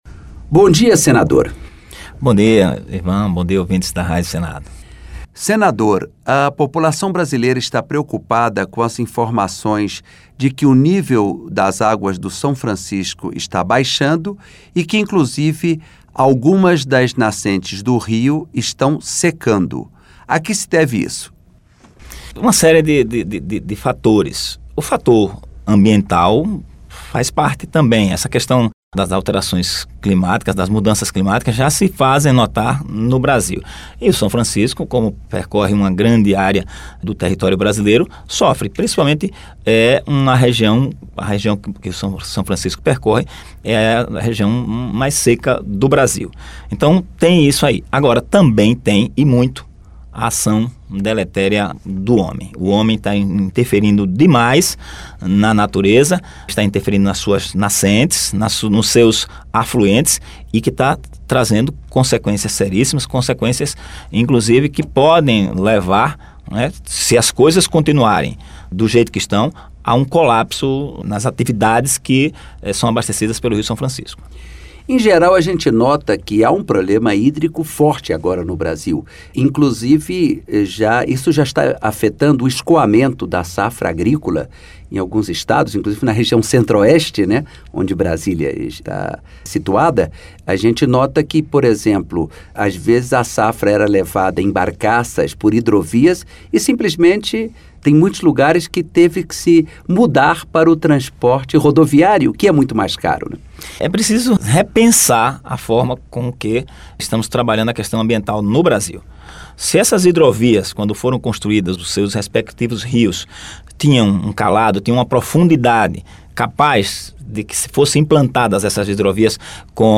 Entrevista com o senador Kaká Andrade (PDT-SE).